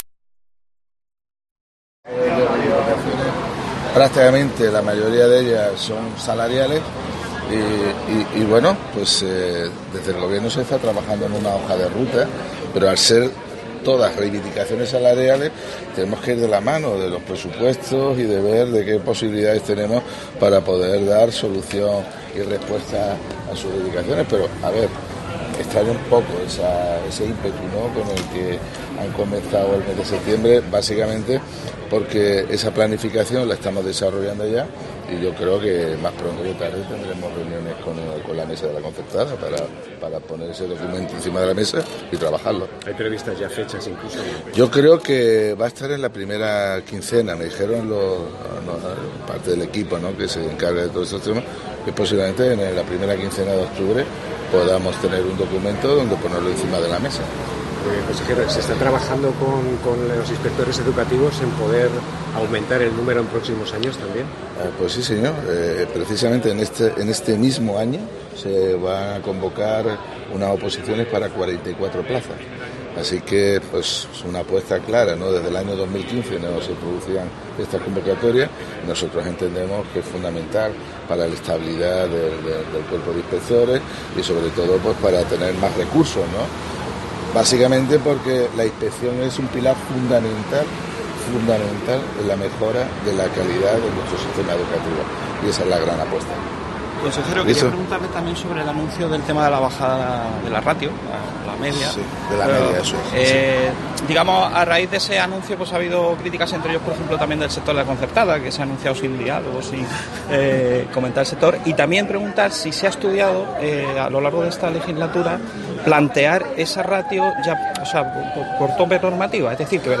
Escucha al Consejero de Educación Javier Imbroda